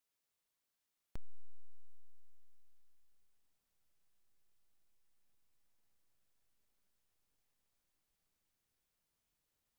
Yeah, the mic was plugged in, here's one with the mic disconnected (can't hear much though). Local mains power here in Greece is 50 Hz Attachments noise2.mp3 noise2.mp3 122.1 KB · Views: 113